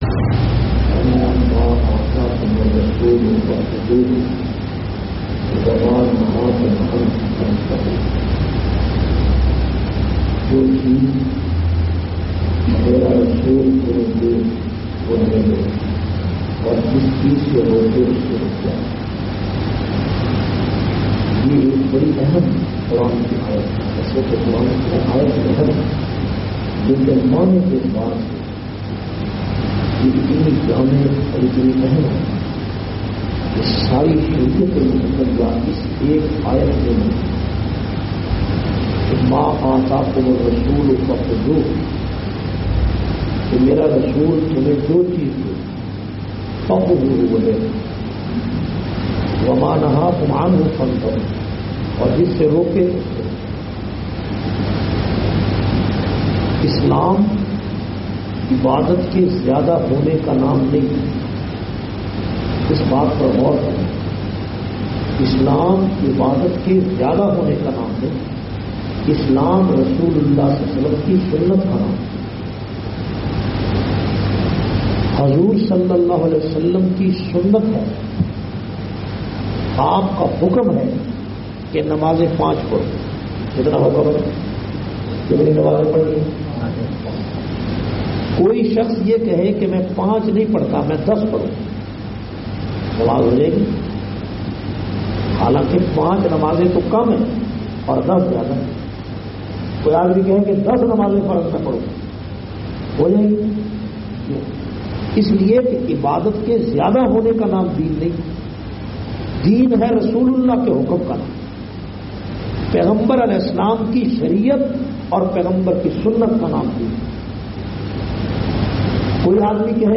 422- Ramzan Aur Quran Madina Masjid Stirling Scotland.mp3